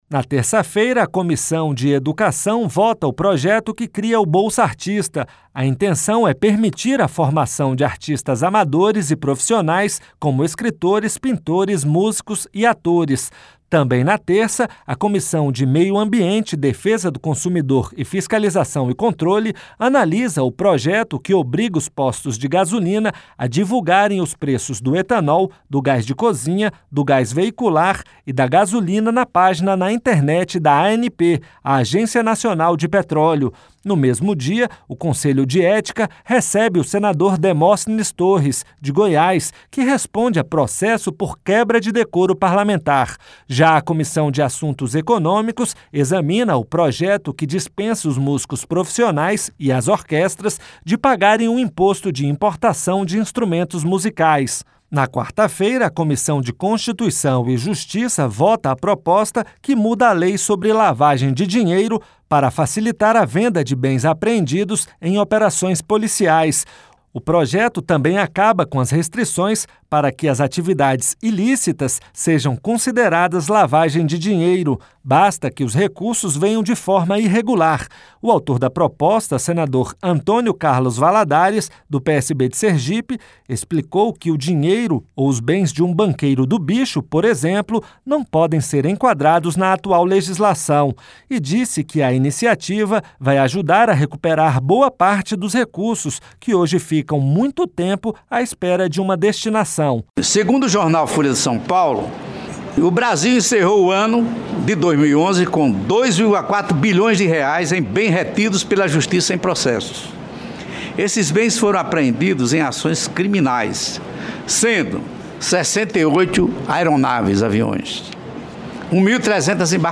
A AGENDA DAS COMISSÕES COM O REPÓRTER